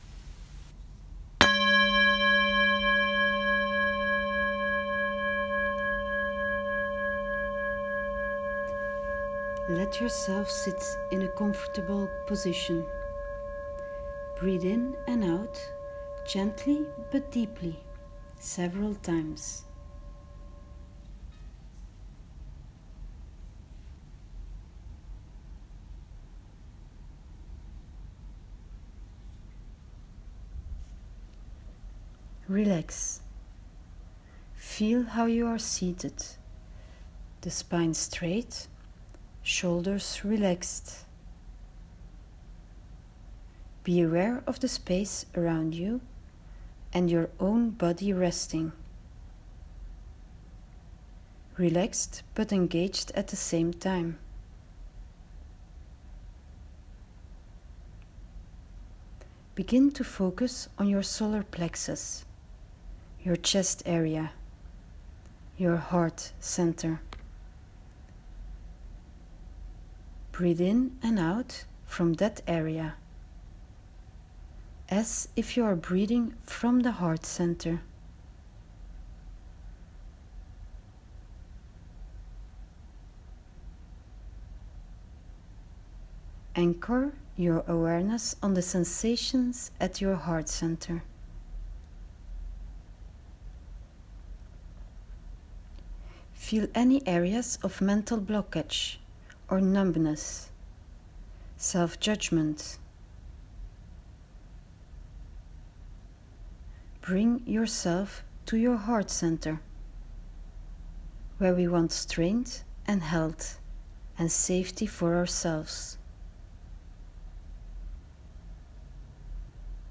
You'll find the guided meditation as a voice recording under the screen with the introduction video.